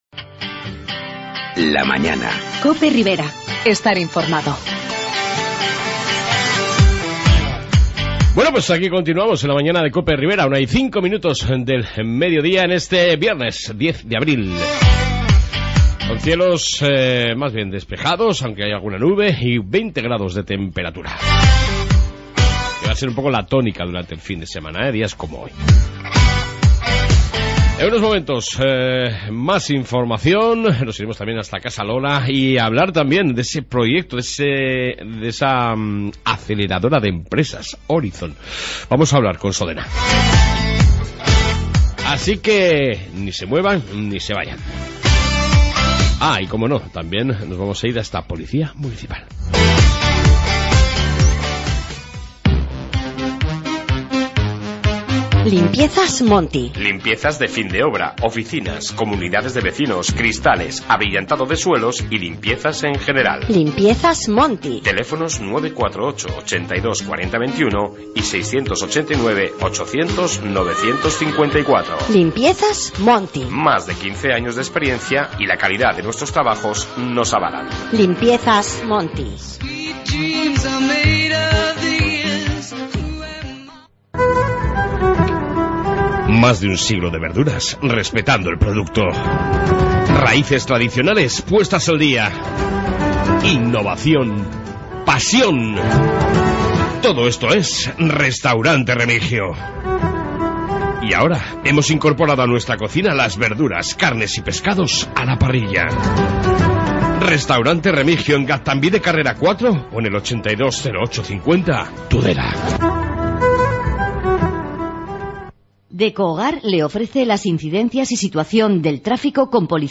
AUDIO: Noticias, Información de Policia municipal y entrevista con Sodena sobre la Aceleradora Orizont y la CAT